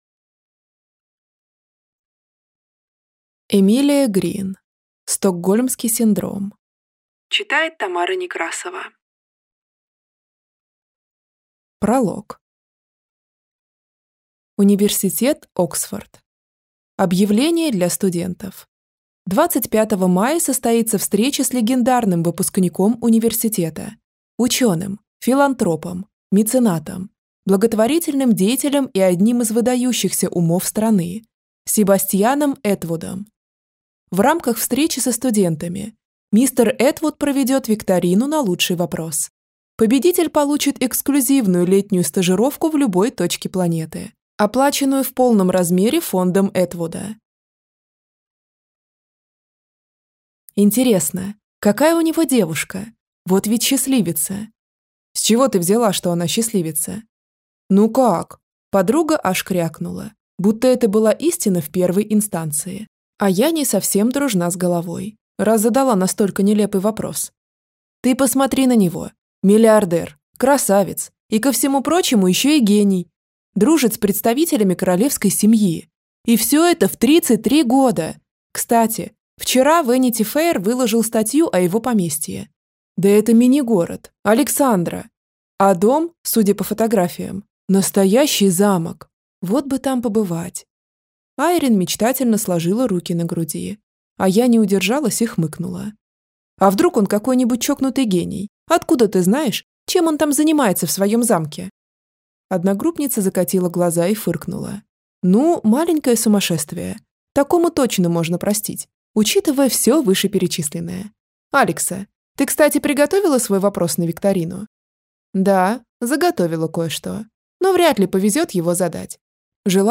Аудиокнига Стокгольмский синдром | Библиотека аудиокниг